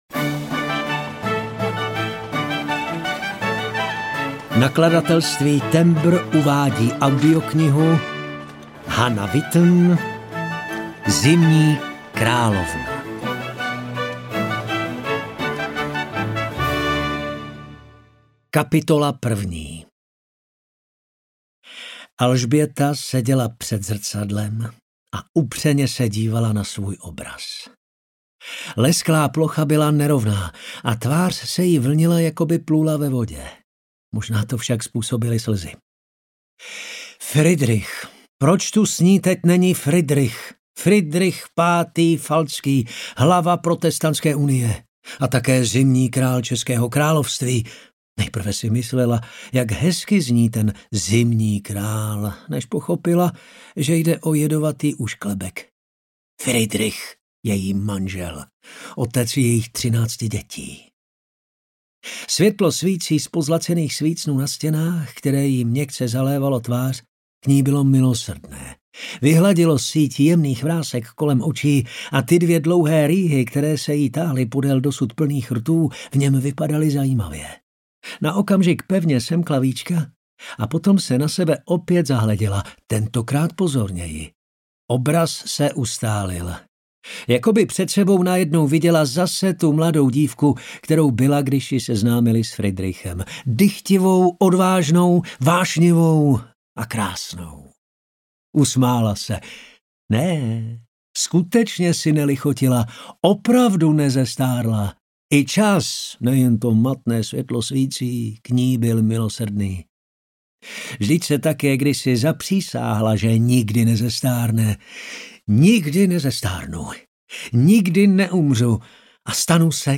Zimní královna audiokniha
Ukázka z knihy